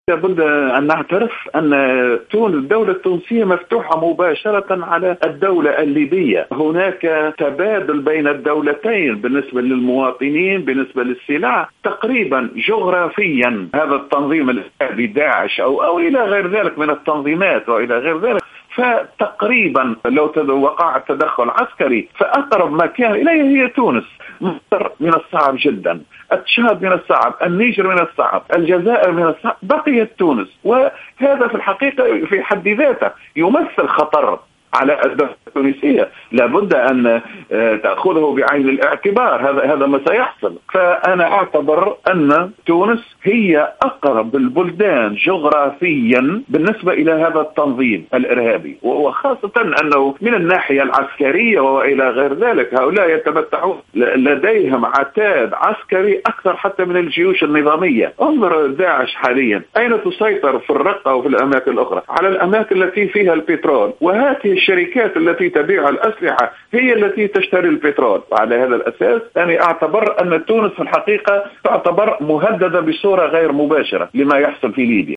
في تصريح لجوهرة "اف ام"